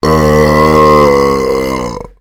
zombie_eat_3.ogg